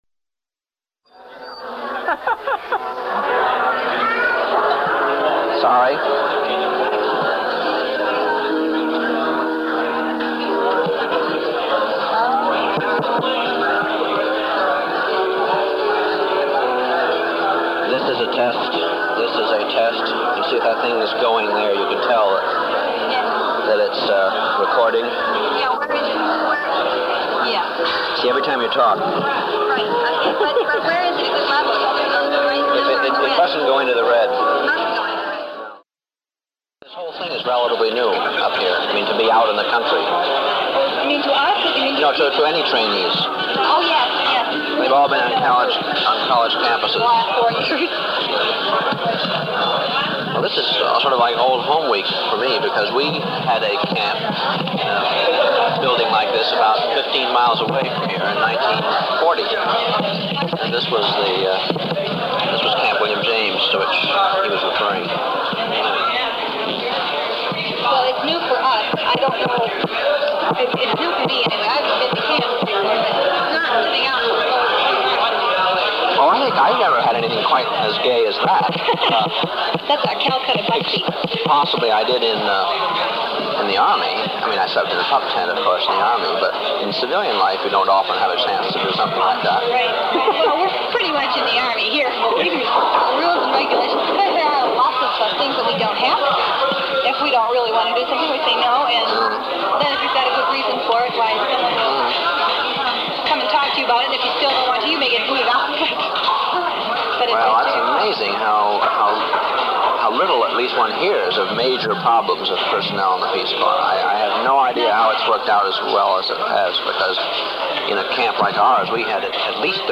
Lecture 02